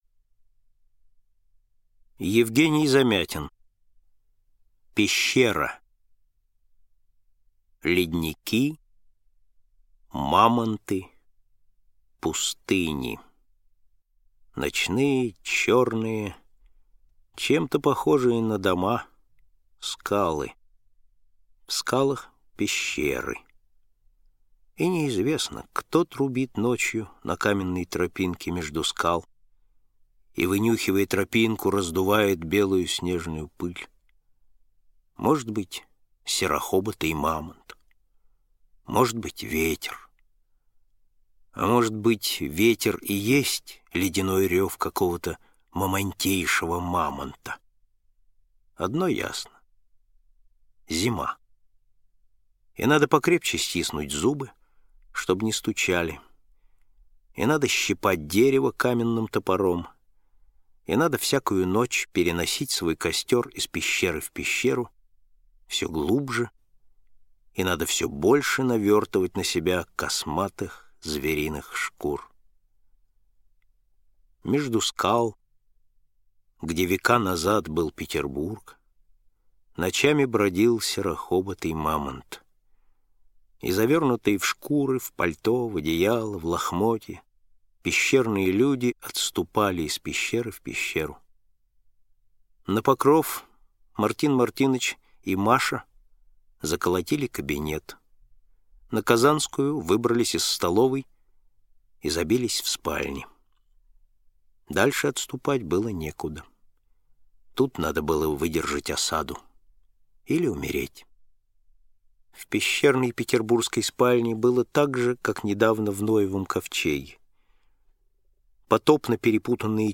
Аудиокнига Избранное | Библиотека аудиокниг